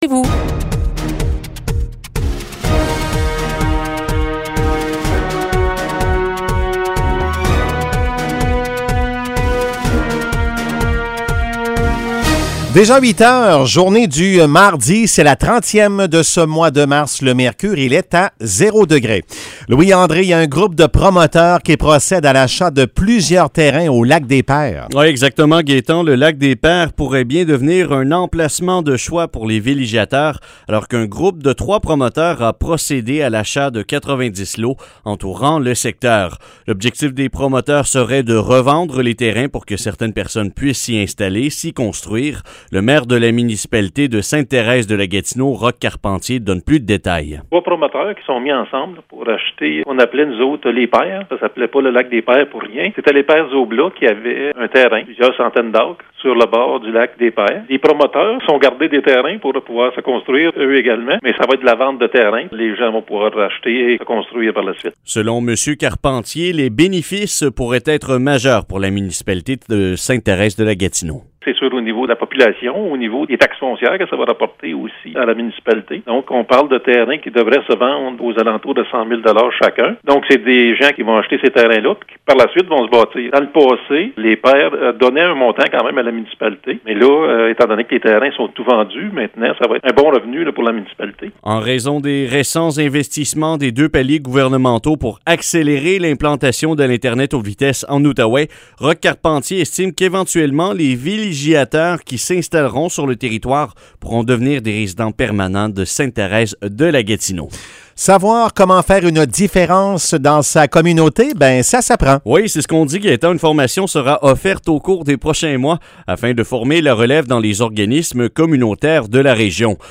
Nouvelles locales - 30 mars 2021 - 8 h